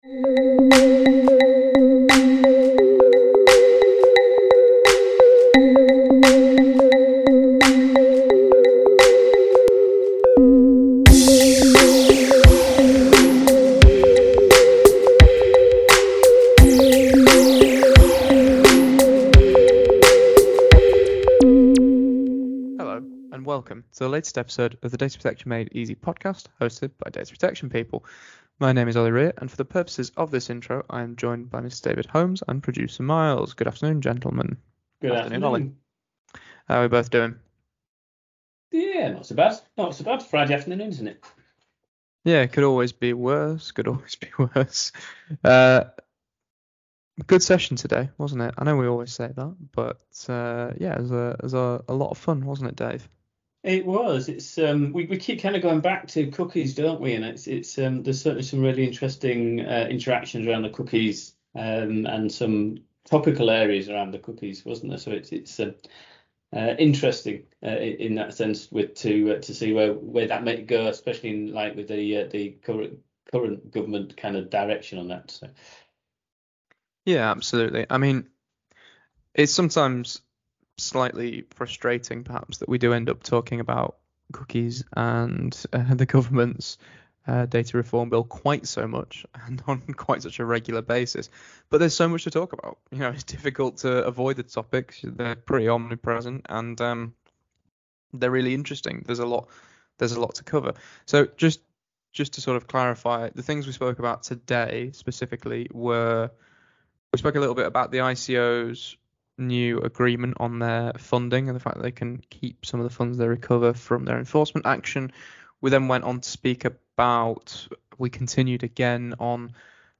In this episode of the Data Protection Made Easy Podcast, our hosts discuss the news of the week to share their views and opinions, particularly on cookies and cookie laws.